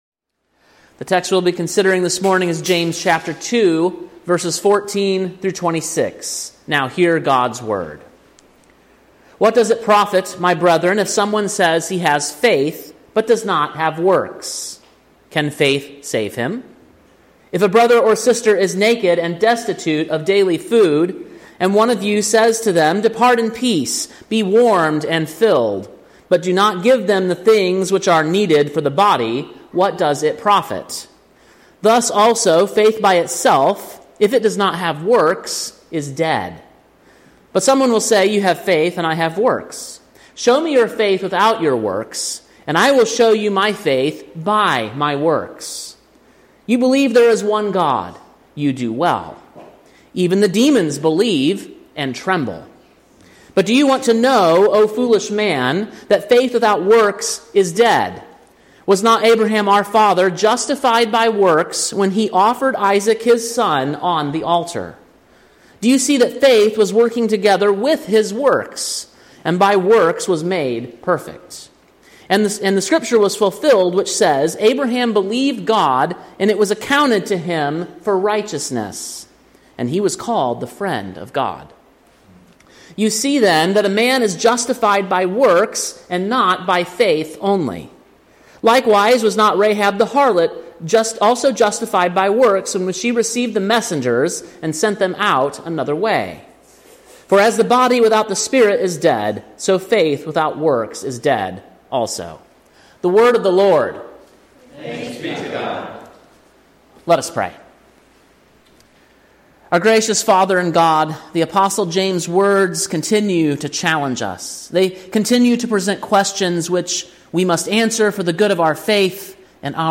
Sermon preached on September 22, 2024, at King’s Cross Reformed, Columbia, TN.